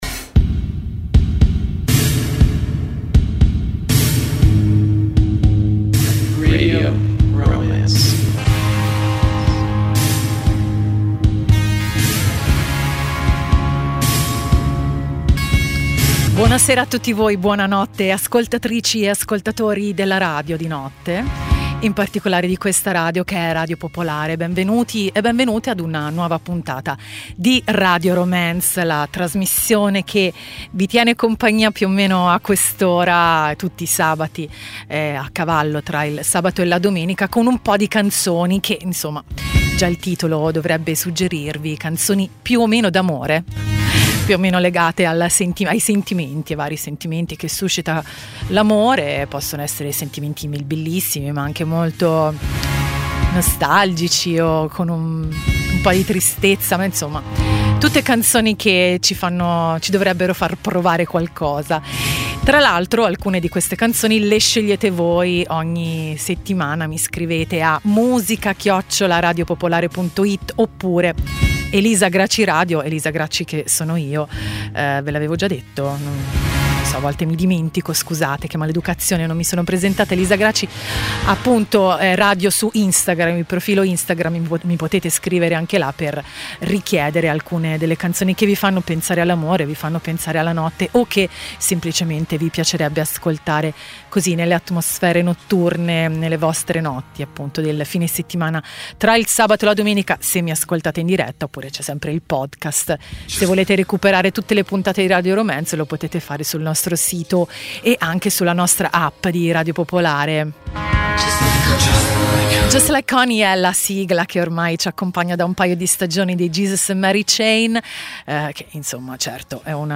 Canzoni d'amore, di desiderio, di malinconia, di emozioni, di batticuore.